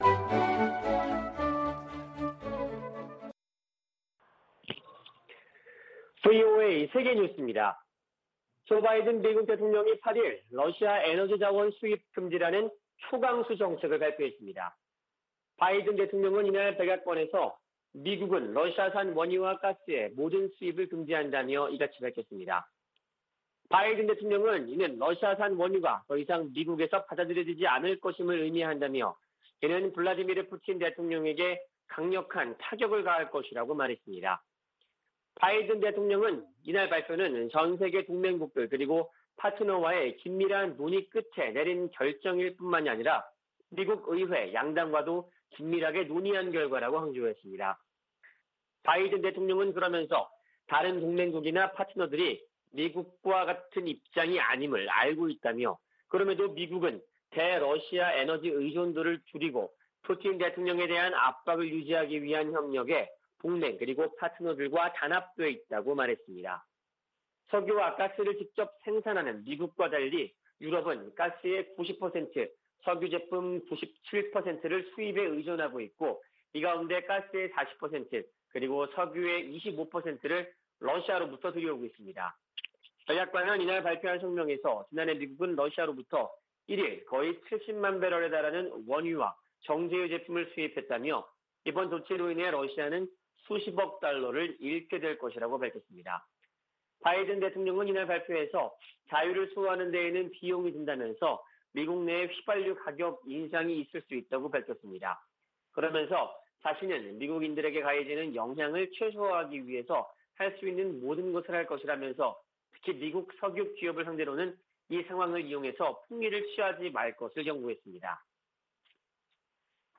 VOA 한국어 아침 뉴스 프로그램 '워싱턴 뉴스 광장' 2022년 3월 9일 방송입니다. 북한의 탄도미사일 발사에 대한 유엔 안보리의 공식 대응이 다시 무산됐습니다. 북한 미사일 도발에 국제사회 비난이 커지는 가운데 북-중-러 삼각 연대가 강화되는 양상을 보이고 있습니다. 북한이 영변과 강선 등지에서 핵 활동을 지속하는 징후가 있다고 국제원자력기구(IAEA) 사무총장이 밝혔습니다.